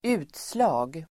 Uttal: [²'u:tsla:g]